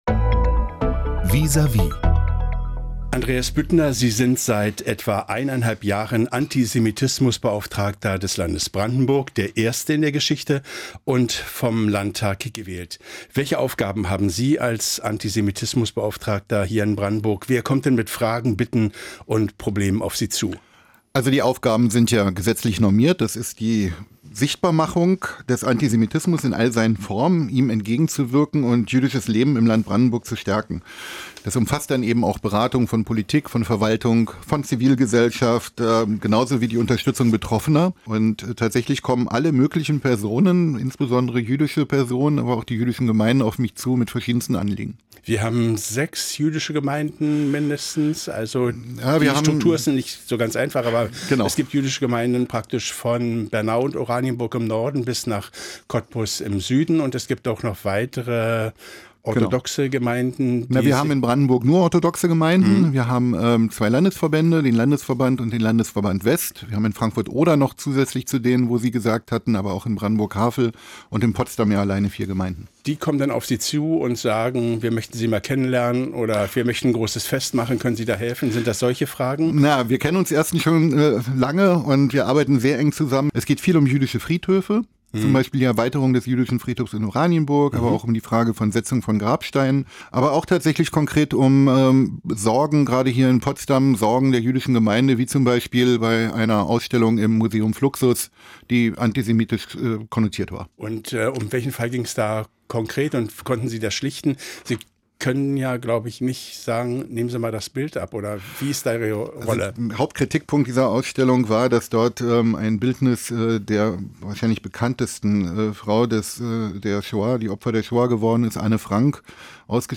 erzählt er im Gespräch